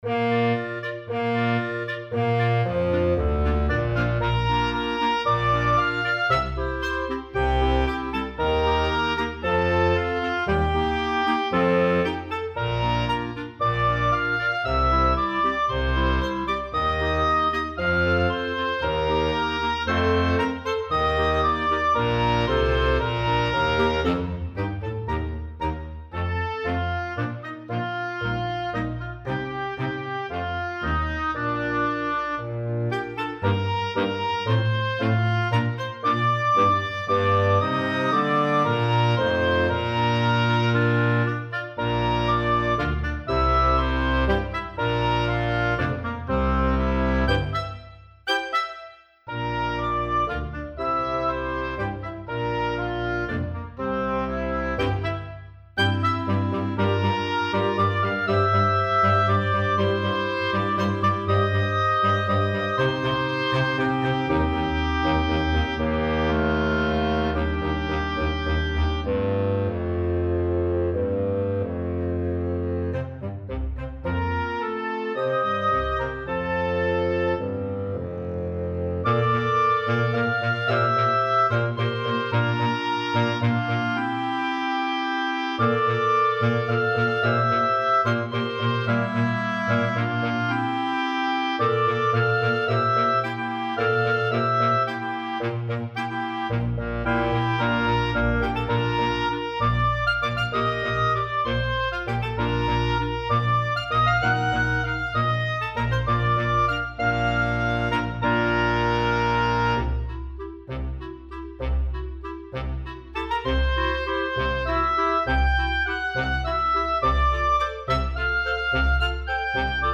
Small Wood Ensemble
digital instruments.